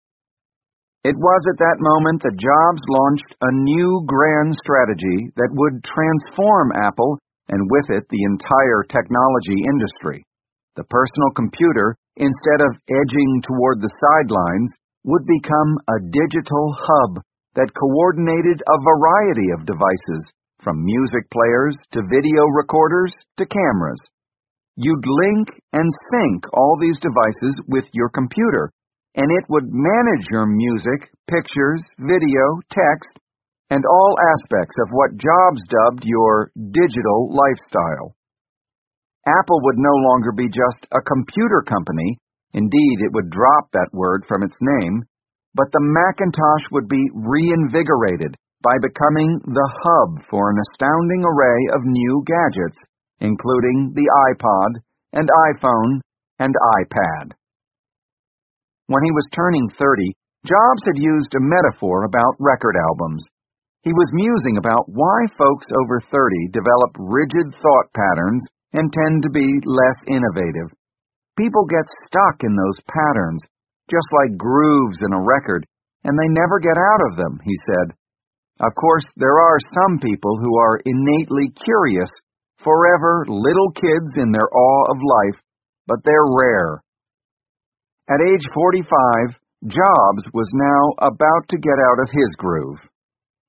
在线英语听力室乔布斯传 第492期:连点成线(3)的听力文件下载,《乔布斯传》双语有声读物栏目，通过英语音频MP3和中英双语字幕，来帮助英语学习者提高英语听说能力。
本栏目纯正的英语发音，以及完整的传记内容，详细描述了乔布斯的一生，是学习英语的必备材料。